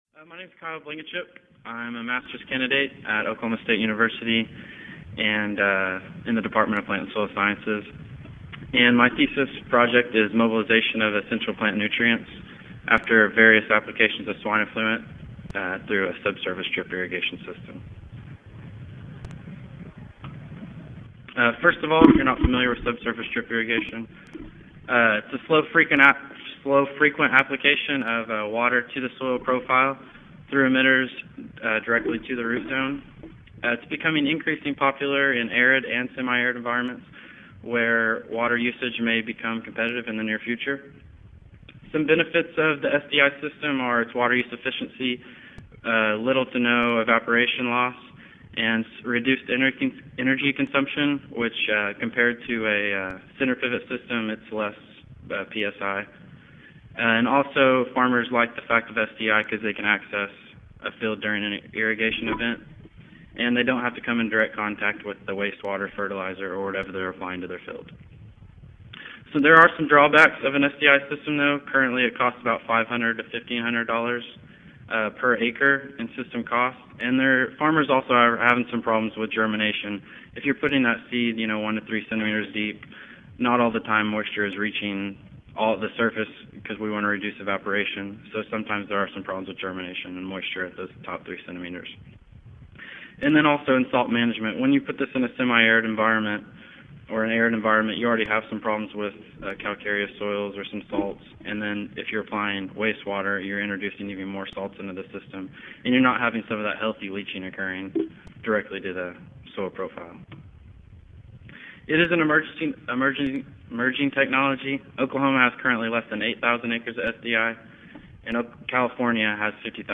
Oklahoma State University Audio File Recorded presentation